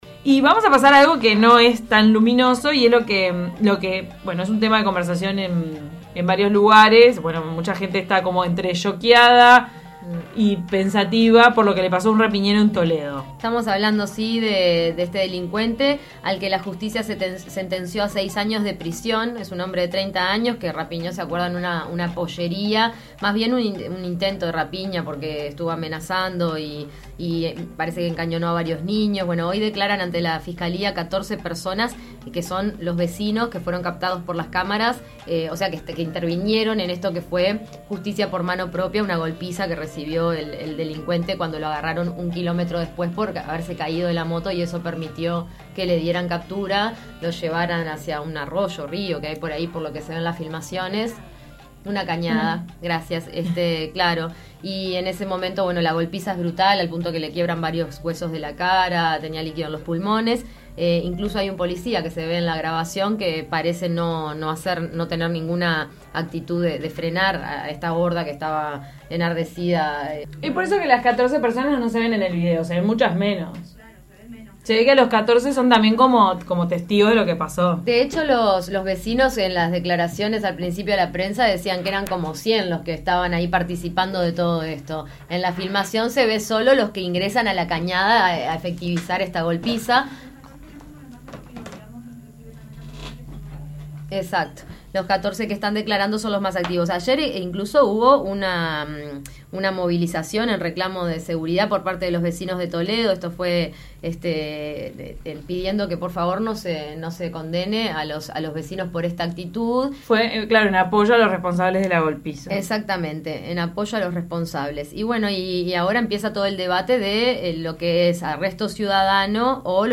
A partir de esto, De taquito a la mañana debatió sobre la justicia por mano propia, y también salió a la calle a preguntarle a la gente qué opina sobre este tema. Muchos opinaron que si bien no es correcto, a falta de acción por parte de la justicia, se deben tomar medidas «desesperadas».